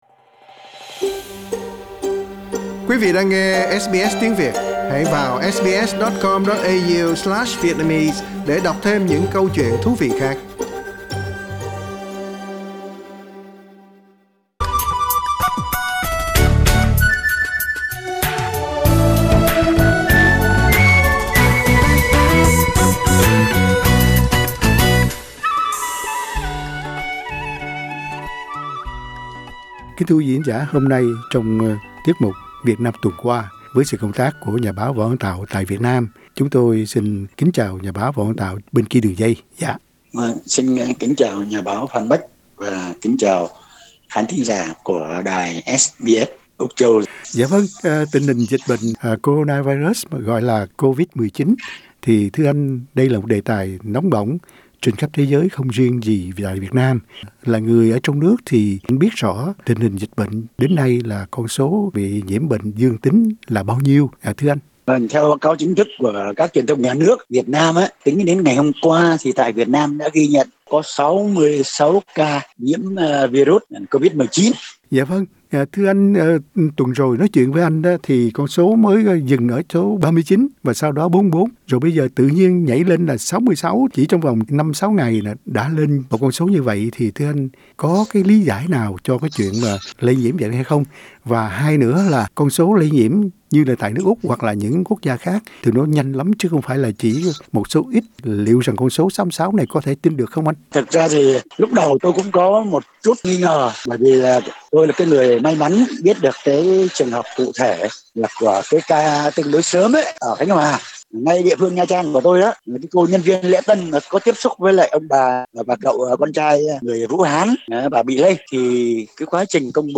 Khách mời tối nay trong buổi mạn đàm